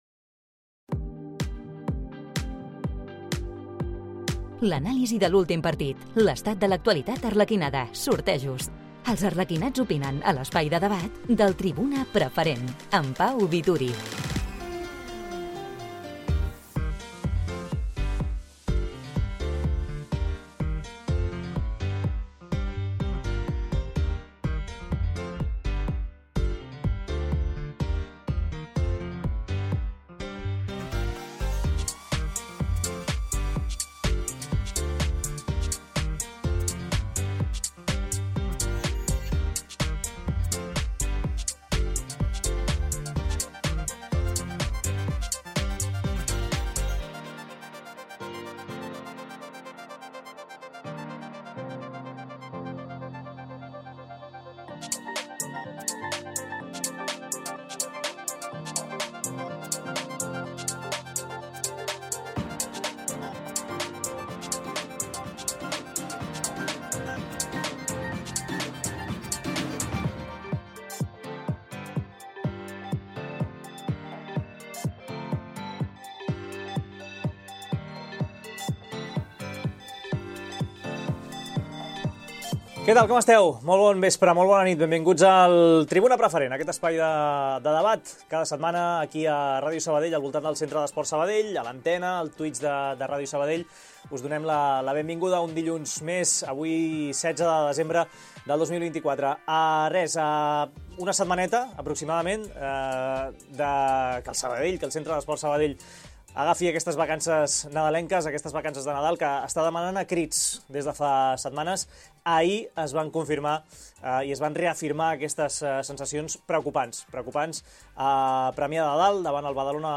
La tertúlia del Centre d’Esports Sabadell. Un espai d’opinió i debat al voltant de l’actualitat i el futur del club arlequinat. Fidel al seus orígens, el programa està obert a la participació directa dels socis, seguidors i aficionats del conjunt sabadellenc.